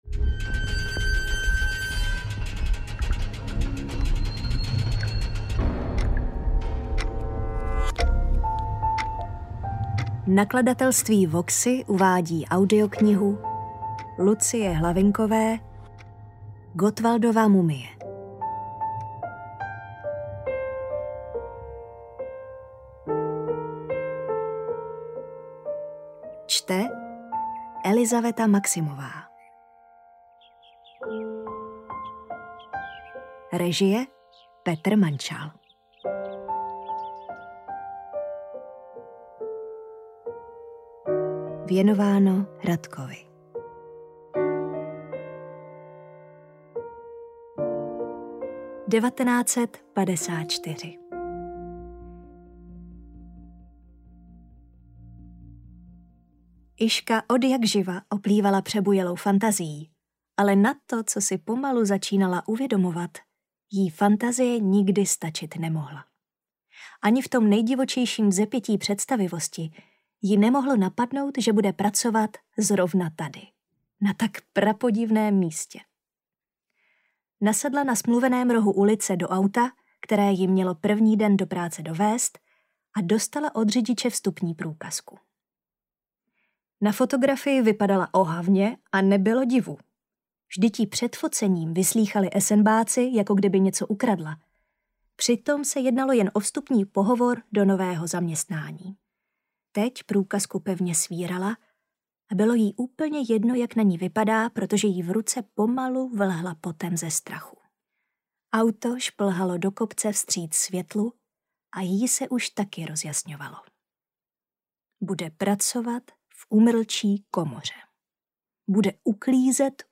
AudioKniha ke stažení, 24 x mp3, délka 6 hod. 53 min., velikost 376,5 MB, česky